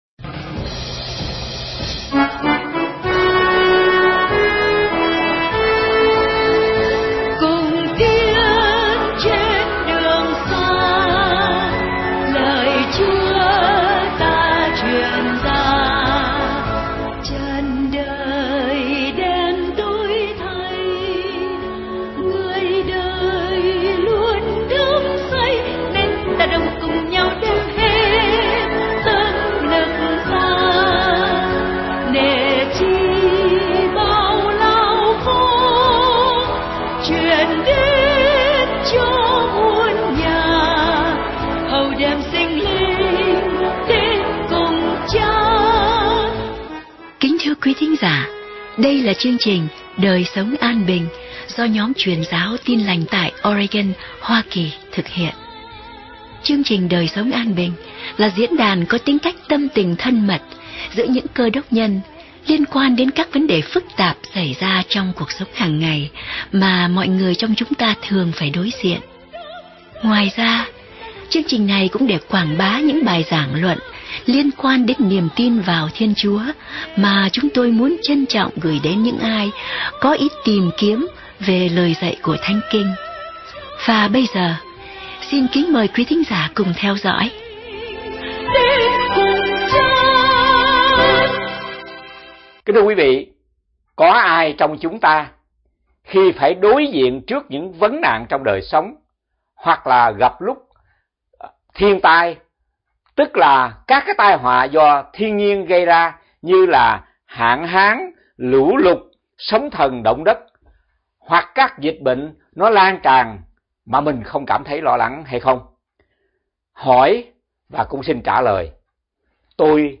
Bài giảng 17 phút Đề tài